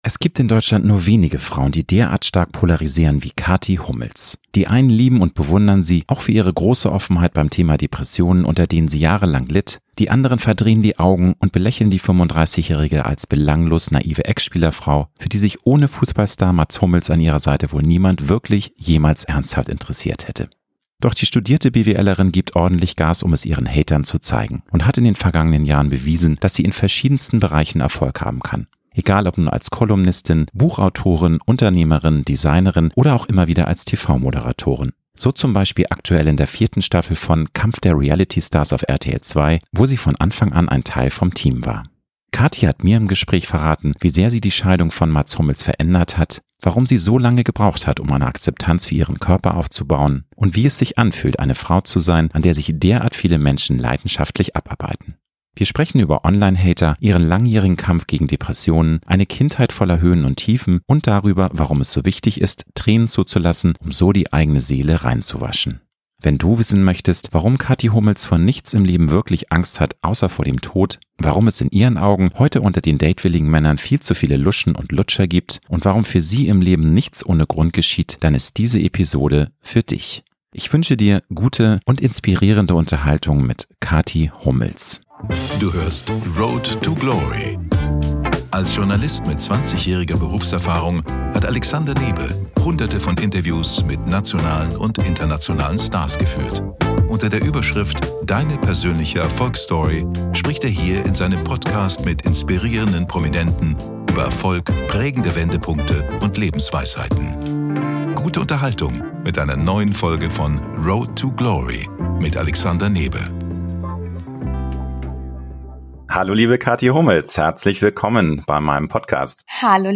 Cathy hat mir im Gespräch verraten, wie sehr sie die Scheidung von Mats Hummels verändert hat, warum sie so lange gebraucht hat, um eine Akzeptanz für ihren Körper aufzubauen und wie es sich anfühlt, eine Frau zu sein, an der sich derart viele Menschen leidenschaftlich abarbeiten. Wir sprechen über Online-Hater, ihren langjährigen Kampf gegen Depressionen, eine Kindheit voller Höhen und Tiefen und darüber, warum es so wichtig ist, Tränen zuzulassen, um so die eigene Seele reinzuwaschen.